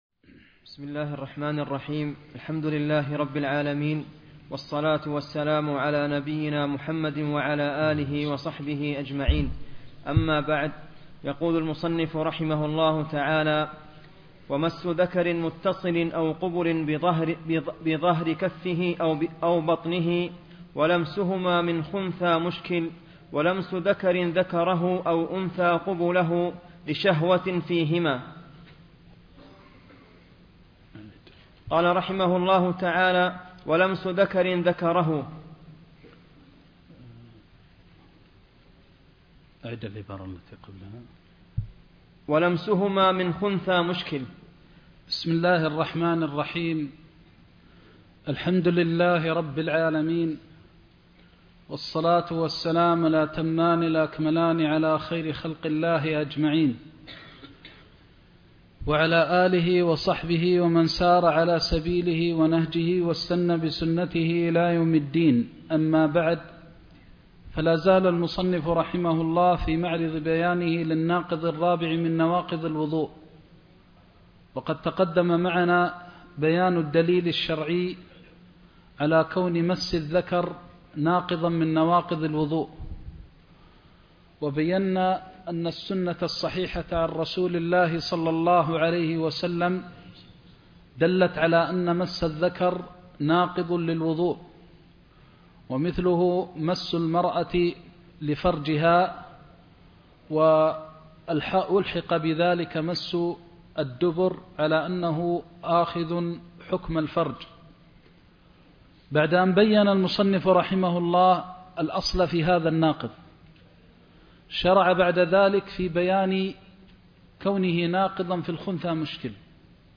زاد المستقنع كتاب الطهارة (20) درس مكة